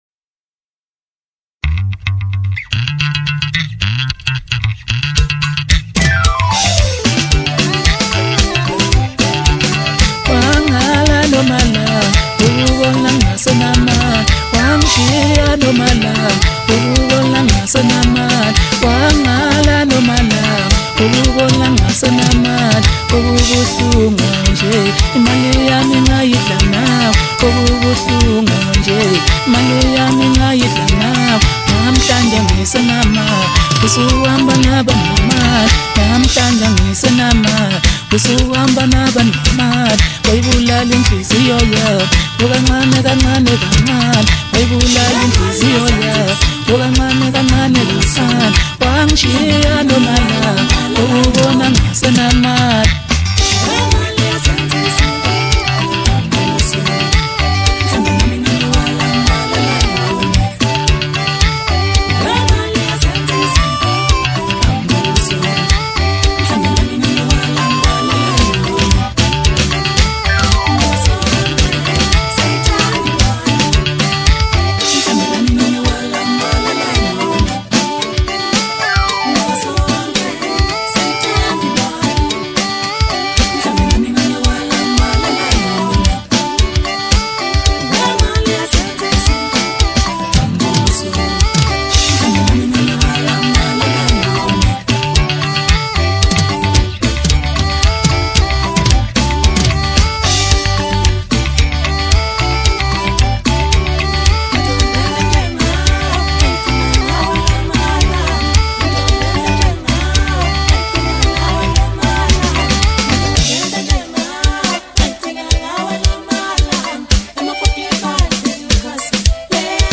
Home » Deep House » Hip Hop » Maskandi
Talented vocalist